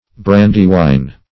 Brandywine \Bran"dy*wine`\, n.